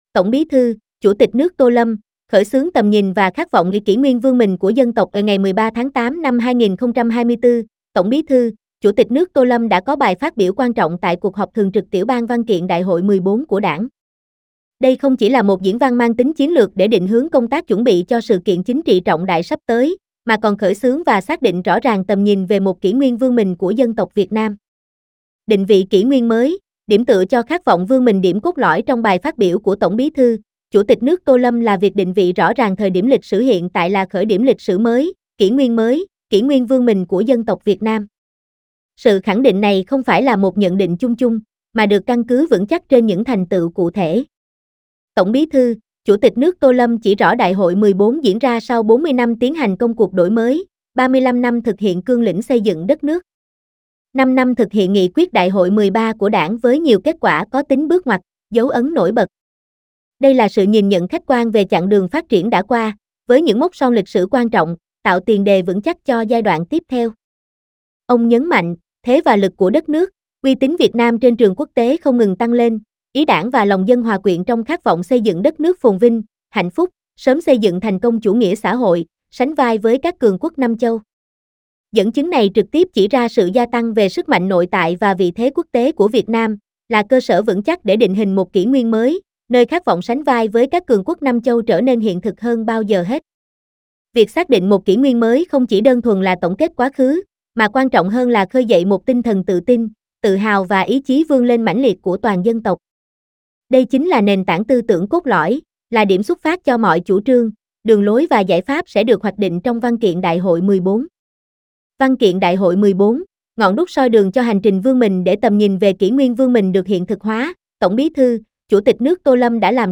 SÁCH NÓI: "VIỆT NAM KHÁT VỌNG VƯƠN MÌNH"